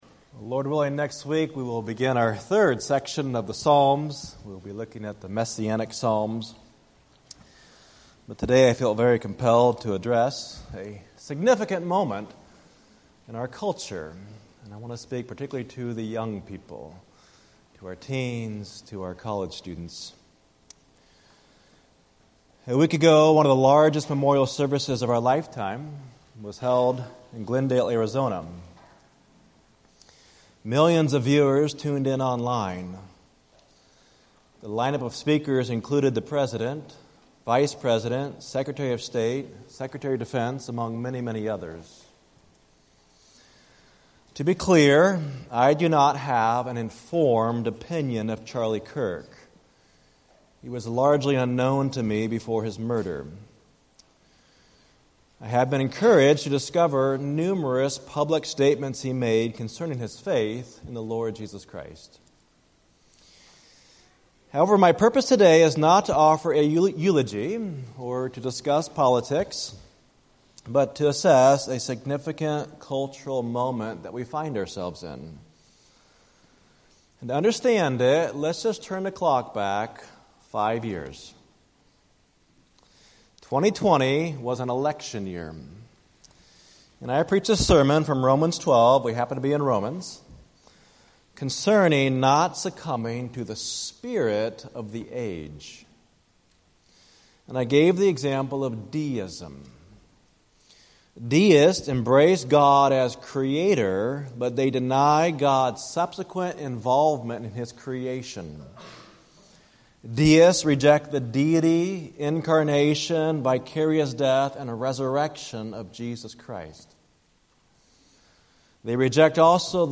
UBC Sermons